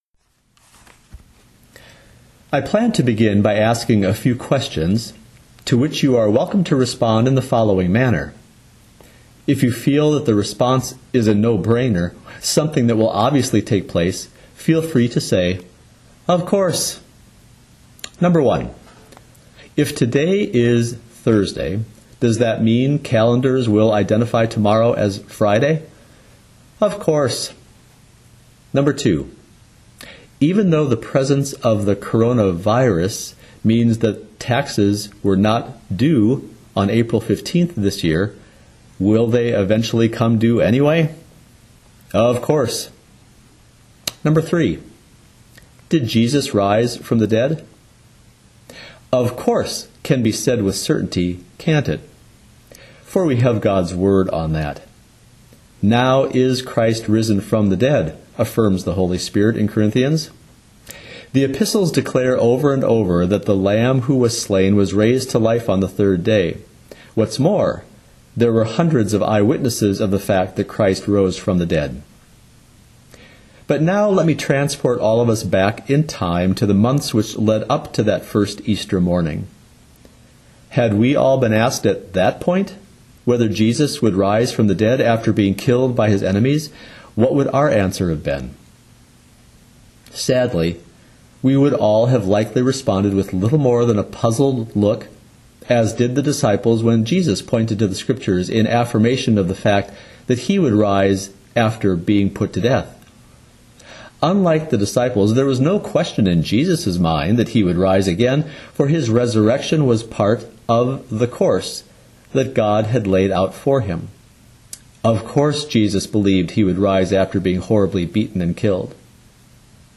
2020-04-16 ILC Chapel — Jesus Rose From the Dead…